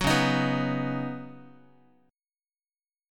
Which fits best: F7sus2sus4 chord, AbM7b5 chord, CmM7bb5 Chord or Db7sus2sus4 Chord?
Db7sus2sus4 Chord